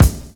BD 38.wav